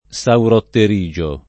vai all'elenco alfabetico delle voci ingrandisci il carattere 100% rimpicciolisci il carattere stampa invia tramite posta elettronica codividi su Facebook sauropterigio [ S auropter &J o ] o saurotterigio [ S aurotter &J o ] s. m. (zool.); pl.